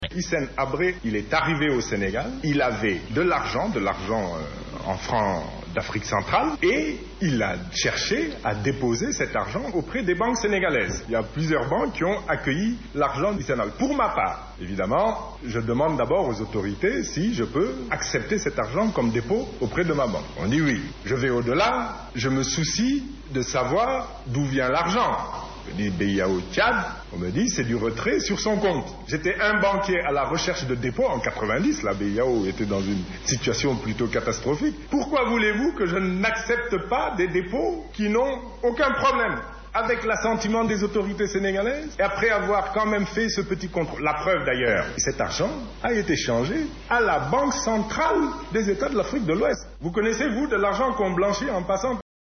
C’est au cours d’un face à face avec la presse que le Premier ministre est revenu sur cette affaire qui, depuis quelques temps, écorne son image d’homme intègre. A l’en croire, on ne peut parler de blanchiment de capitaux d’autant plus que la Loi ayant trait avec cette pratique ne date que de 2004 alors que les faits soulevés sont de 1990.
ECOUTEZ le Premier ministre au micro de nos confrères de RFM. abdoulmbayeblanchiment.mp3 (818.81 Ko)